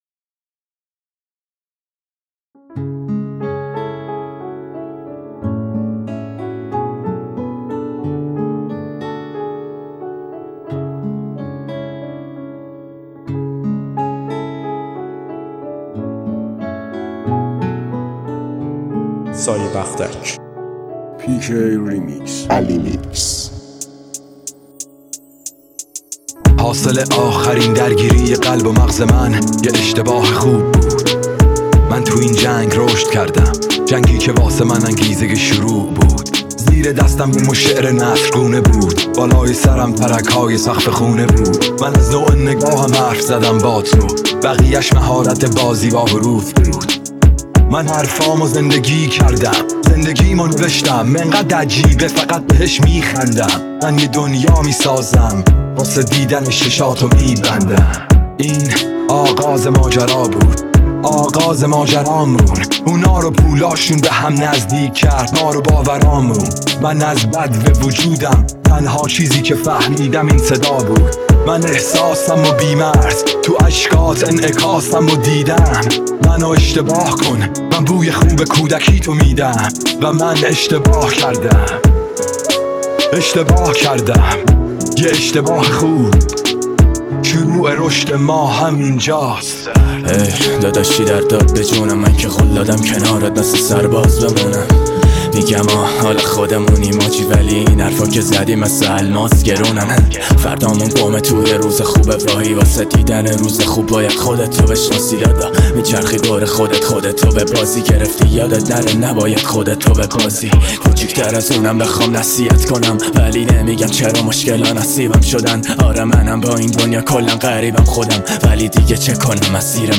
موسیقی رپ ایران
Iranian Hip Hop New Persian Rap